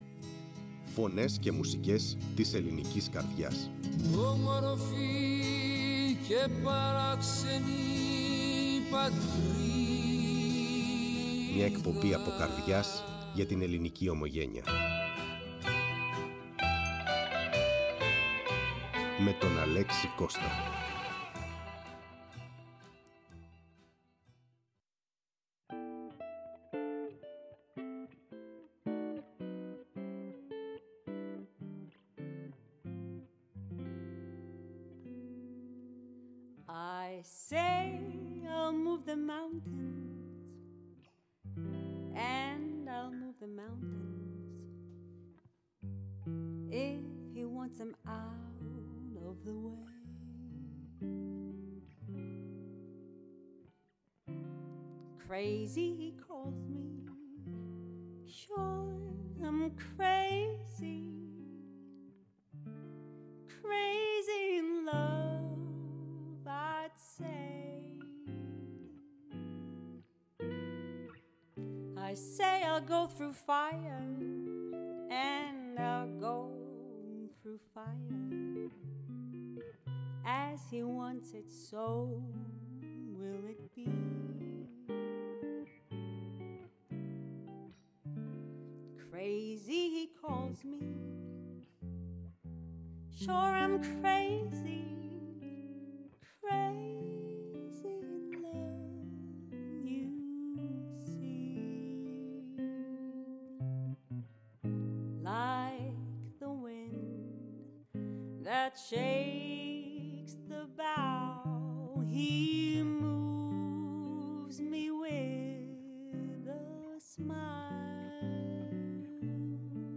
Φωνές και Μουσικές”, ζωντανά στο στούντιο της Φωνής της Ελλάδας.